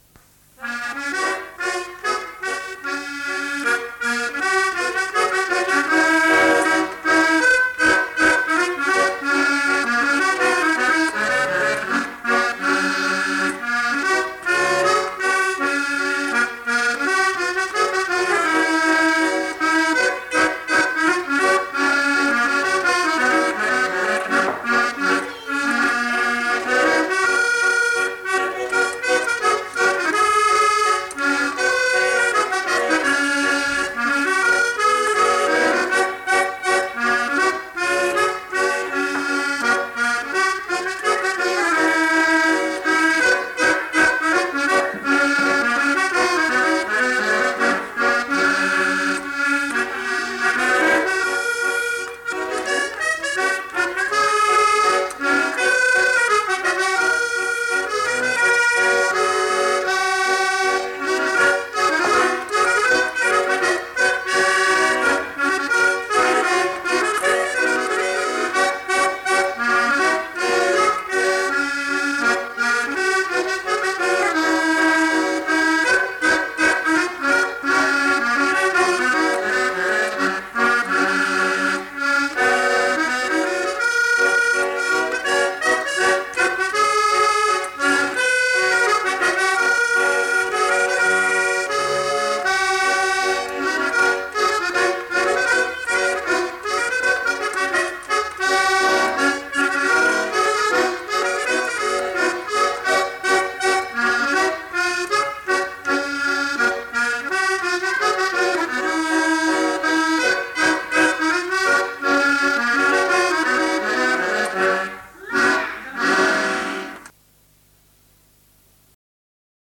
Lieu : Mas-Cabardès
Genre : morceau instrumental
Instrument de musique : accordéon diatonique
Danse : scottish
Notes consultables : Le joueur d'accordéon n'est pas identifié.